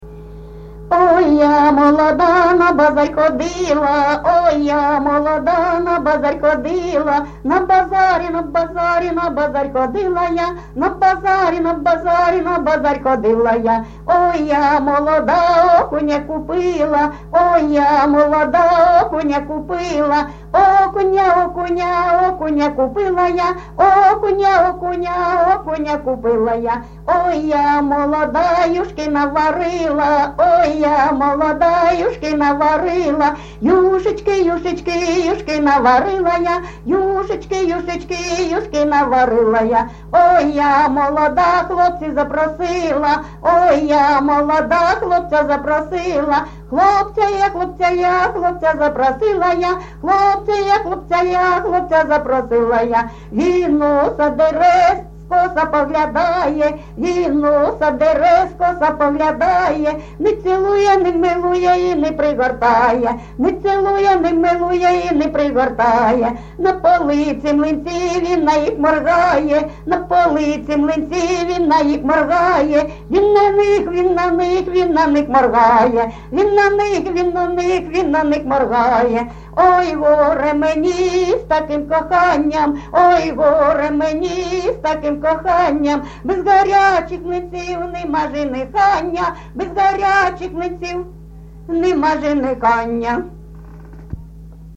ЖанрЖартівливі
Місце записум. Часів Яр, Артемівський (Бахмутський) район, Донецька обл., Україна, Слобожанщина